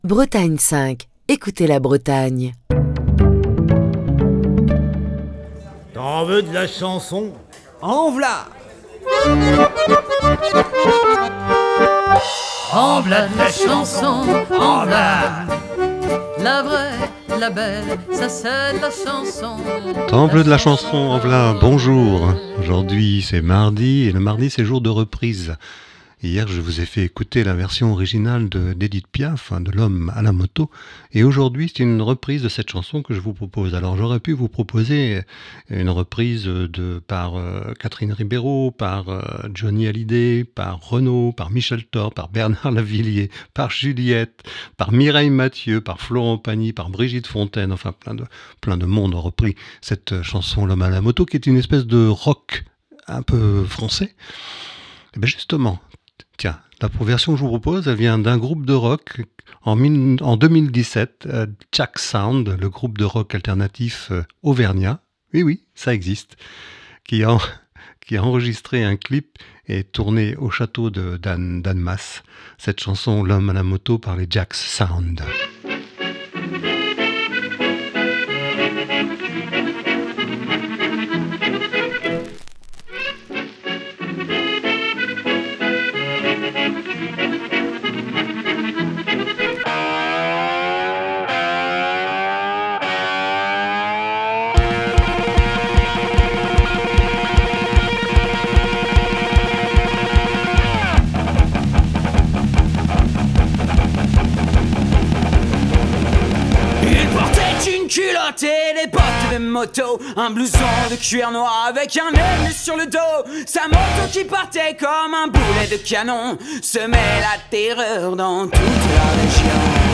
version rock